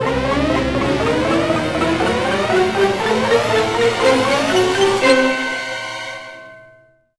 boss_spawn_seq_01.wav